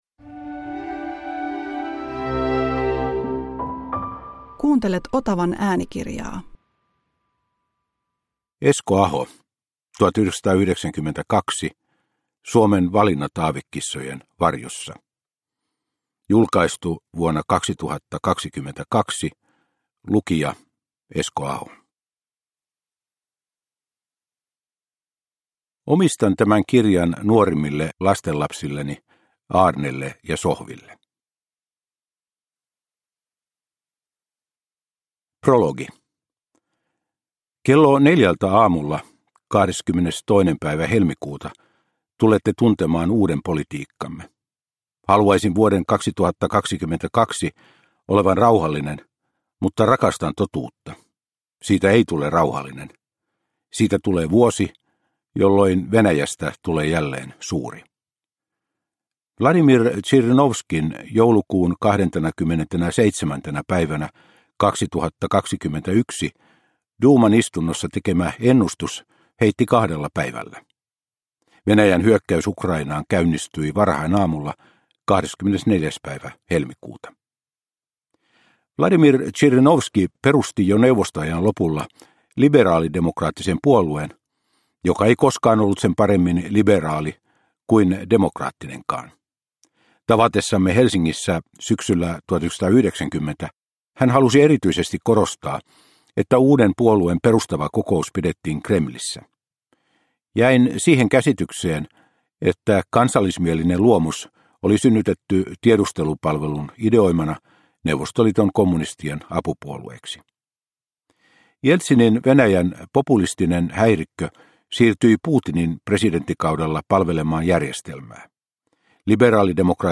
1992 – Ljudbok – Laddas ner
Uppläsare: Esko Aho